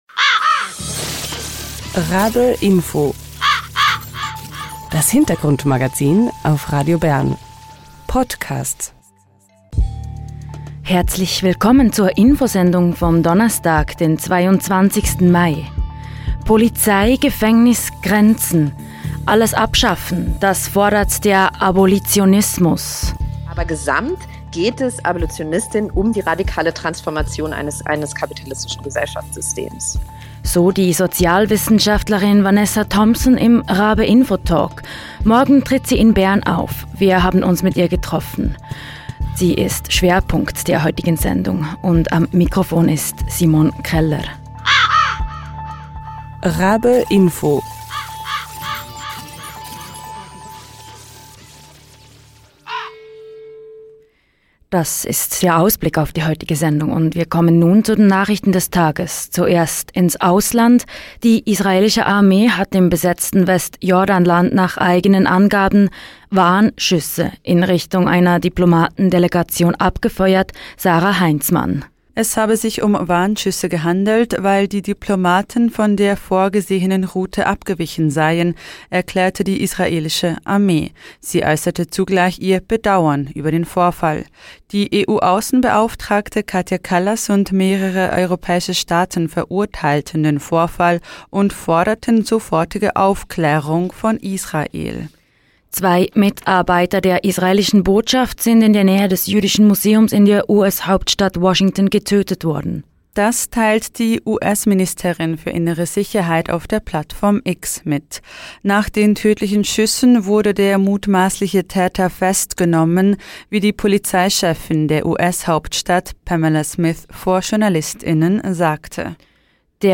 Gespräch ~ Radio RaBe Podcast